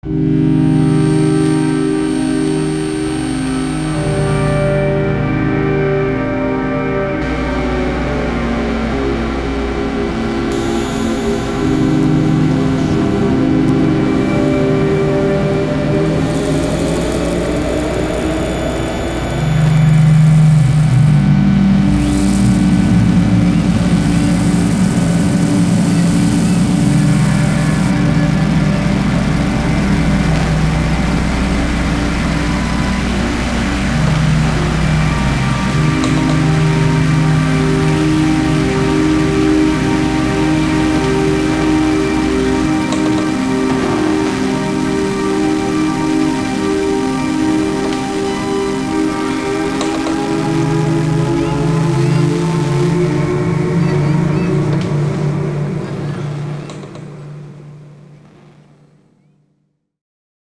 Fx